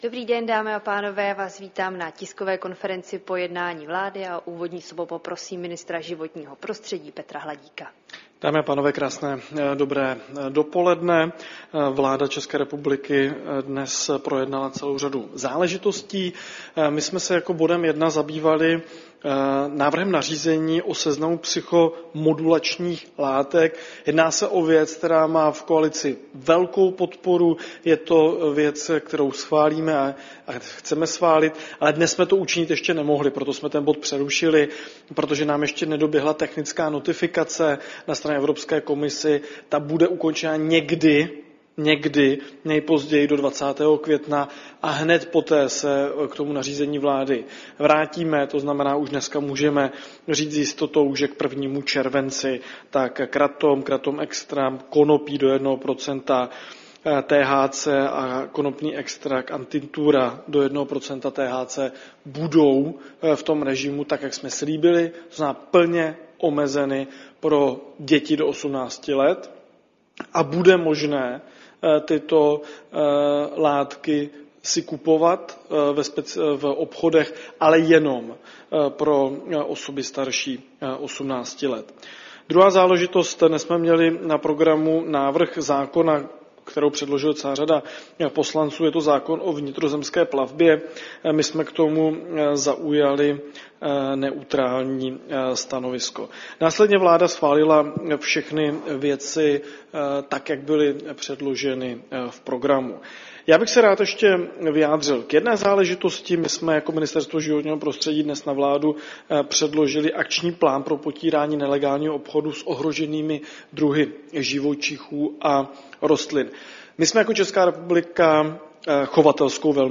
Tisková konference po jednání vlády, 19. února 2025